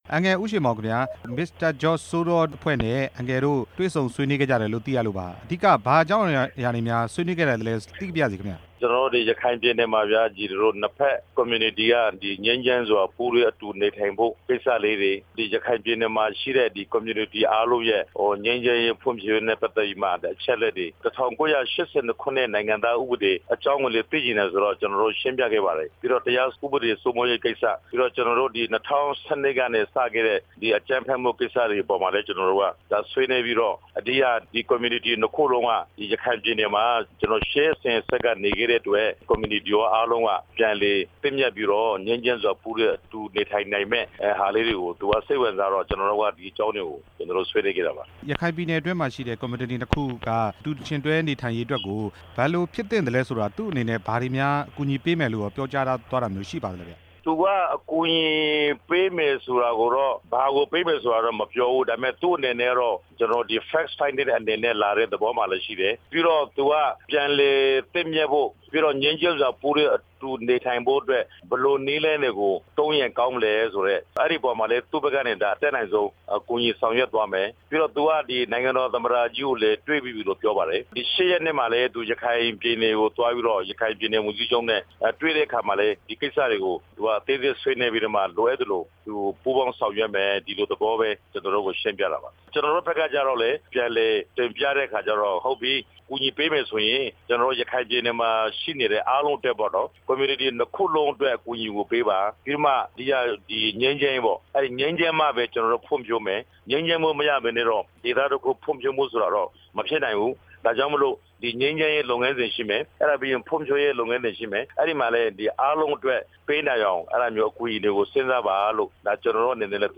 အမေရိကန်နိုင်ငံအခြေစိုက် Open society တည်ထောင်သူနဲ့ ဆက်သွယ်မေးမြန်းချက်